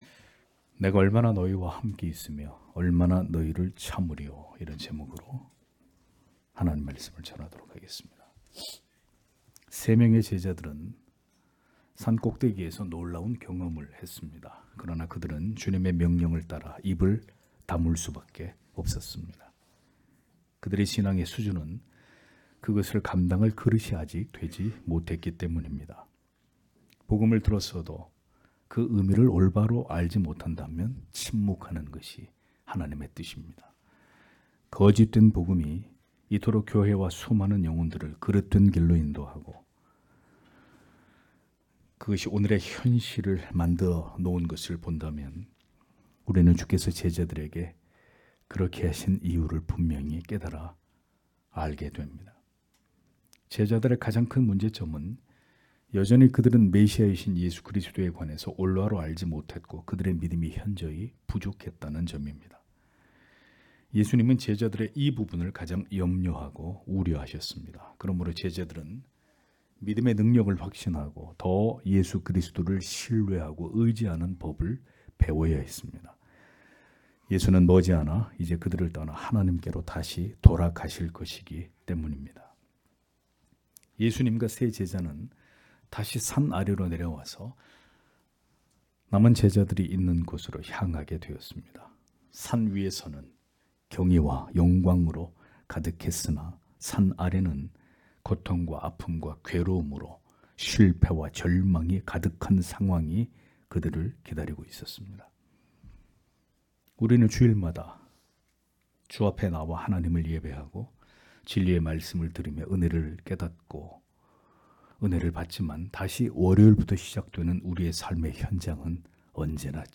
주일오전예배 - [마가복음 강해 36] 내가 얼마나 너희와 함께 있으며 얼마나 너희를 참으리요 (막 9장 14-29절)